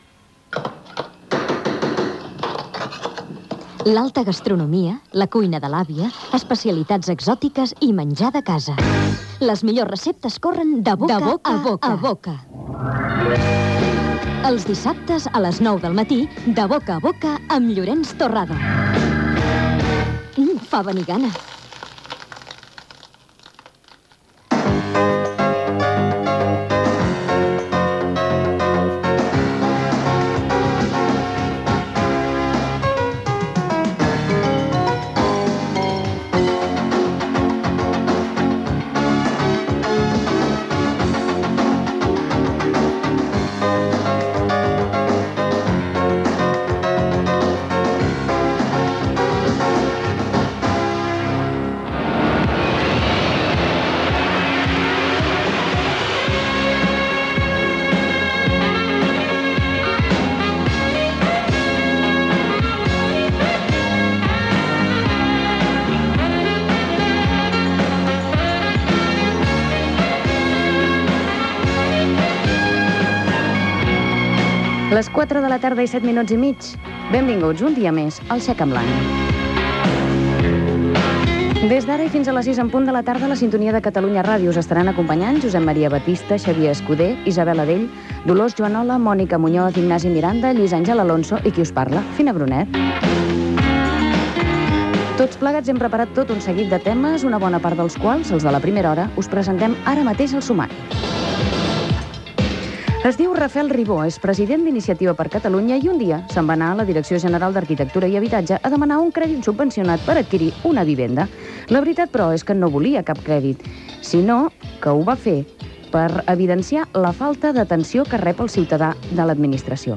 Promoció del programa "De boca a boca", sintonia de l'emissora, benvinguda al programa, equip, sumari, entrevista al president d'Iniciativa per Catalunya Rafael Ribó
Entreteniment